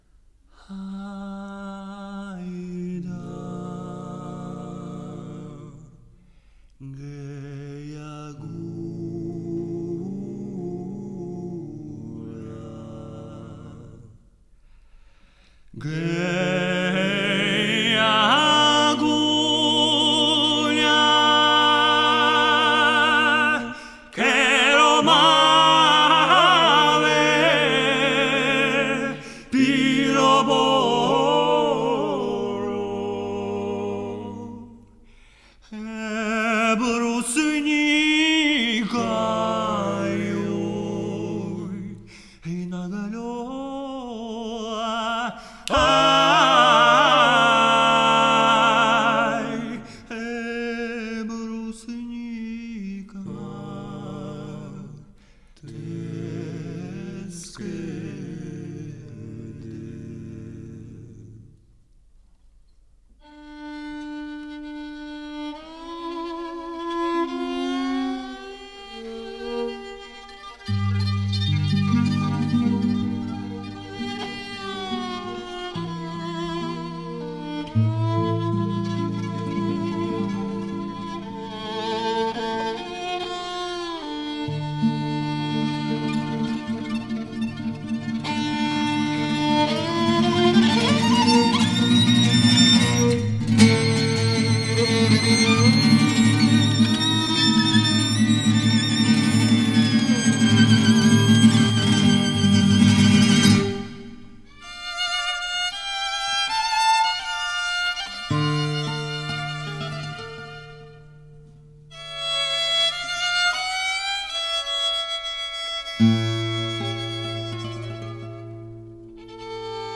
充满了优雅迷人的风情。
俄罗斯的吉普赛音乐